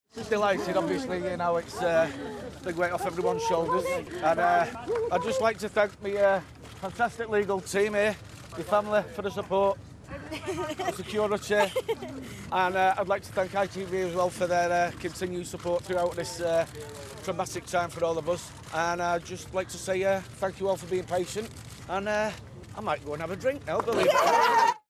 Speaking on the steps of Manchester Crown Court as he's cleared of all charges against him.